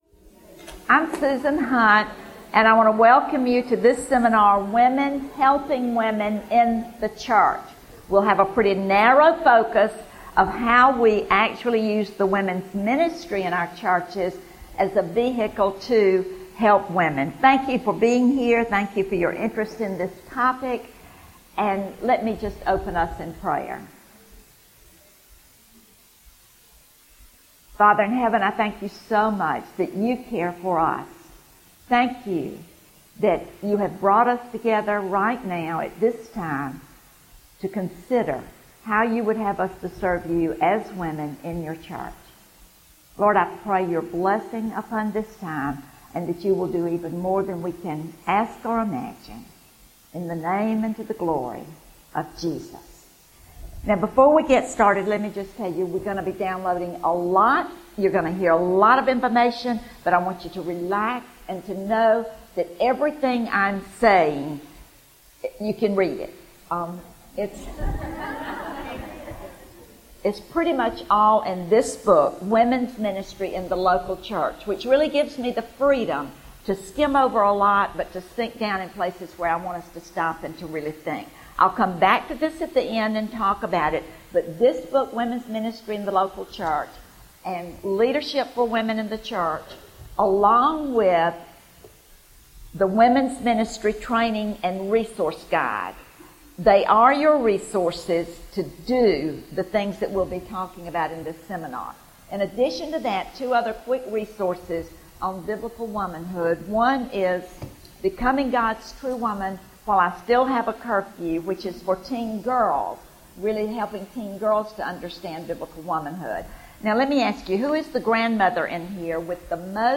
Women Helping Women In the Church | Revive '13 | Events | Revive Our Hearts